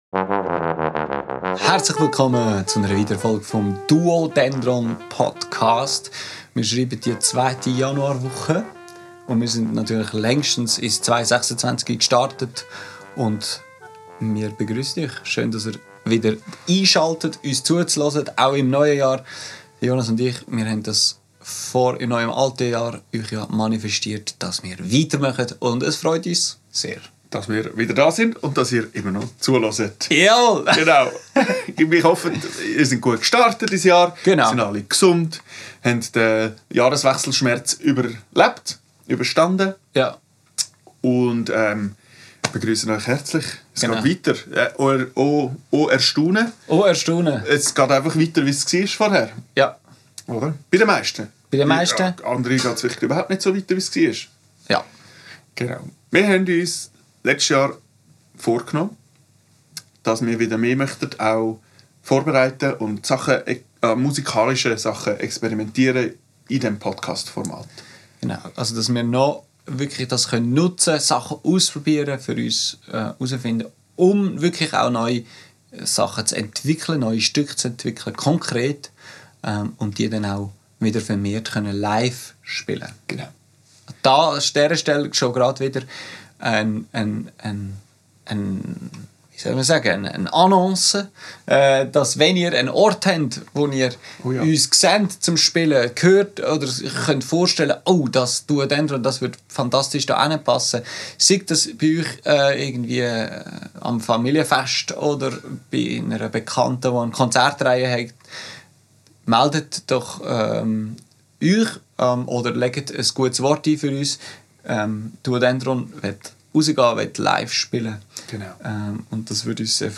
Wir testen in dieser Folge eine neue Textur und ihr dürft den Entstehungsprozess live mitverfolgen.